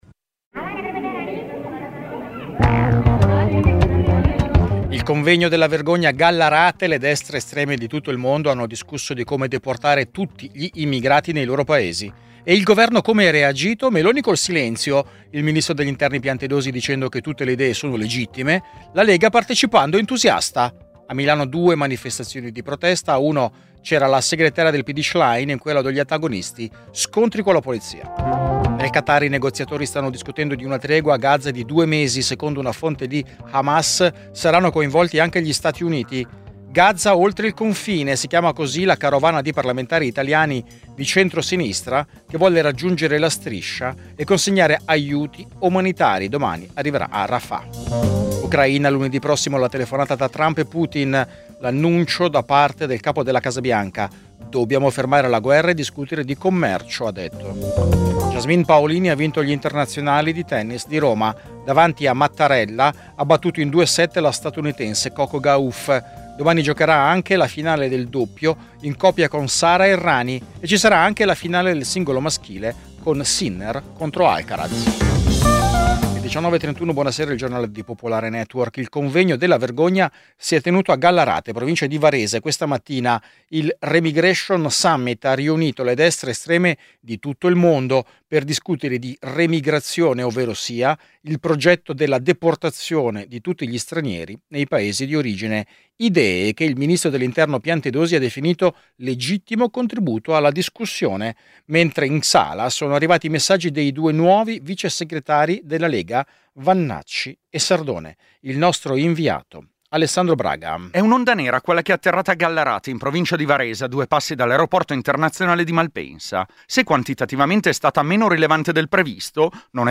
Giornale radio nazionale - del 17/05/2025 ore 19:29